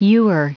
Prononciation du mot ewer en anglais (fichier audio)
Prononciation du mot : ewer